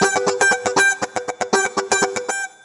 120FUNKY21.wav